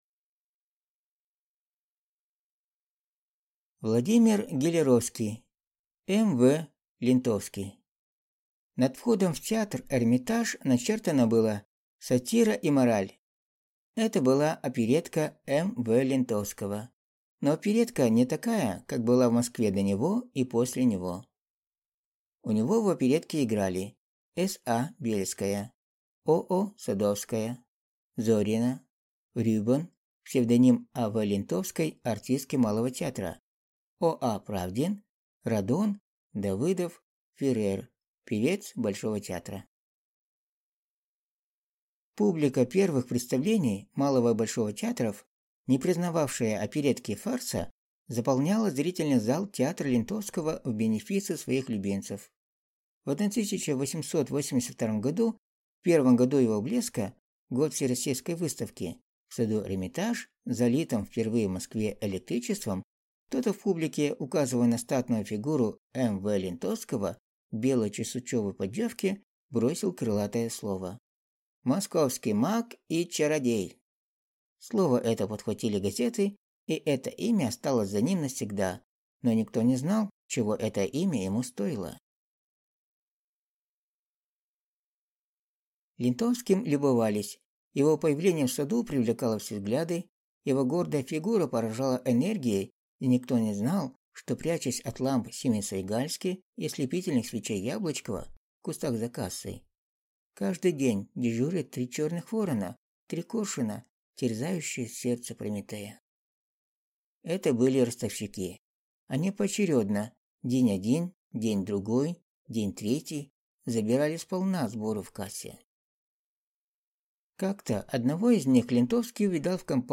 Аудиокнига М. В. Лентовский | Библиотека аудиокниг